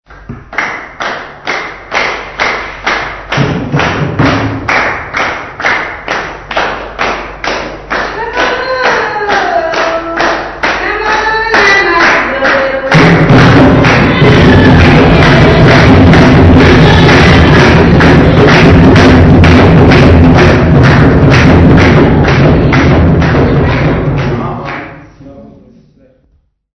Andara Music Workshop Participant
Folk music
Field recordings
Composition performed by music workshop participants.
96000Hz 24Bit Stereo